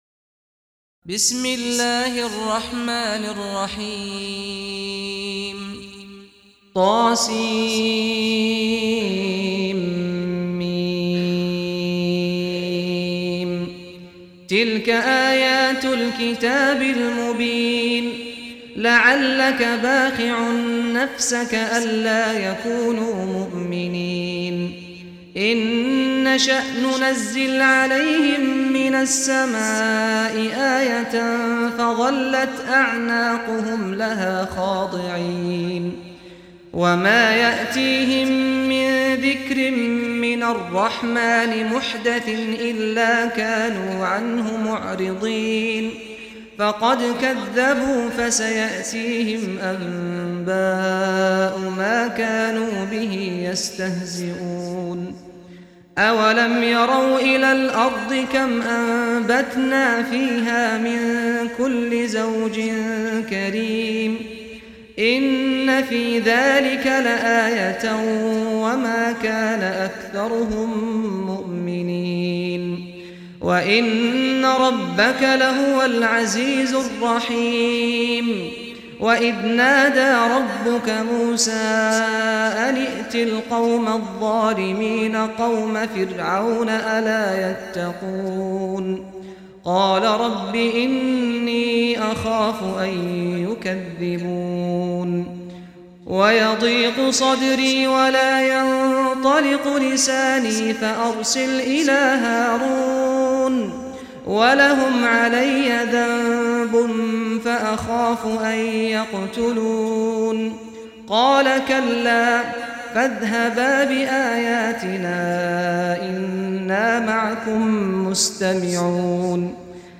سورة الشعراء - سعد بن سعيد الغامدي (صوت - جودة فائقة. التصنيف: تلاوات مرتلة